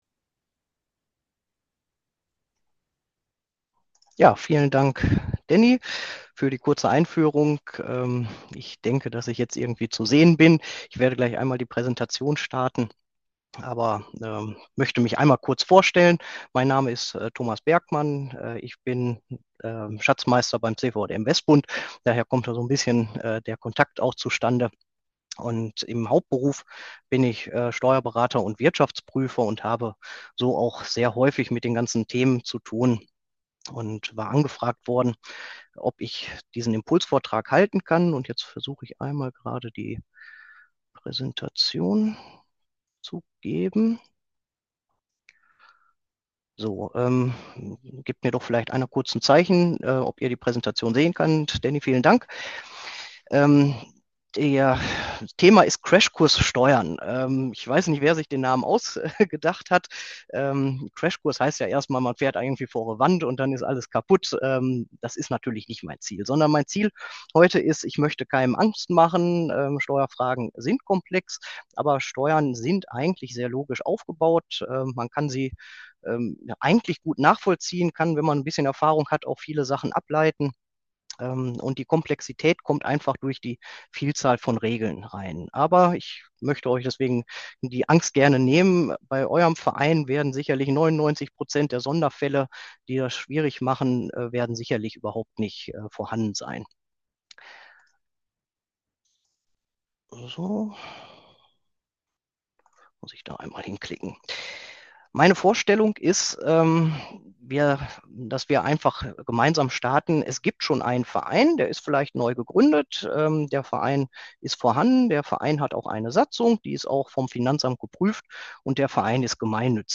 Themenbereich: Vortrag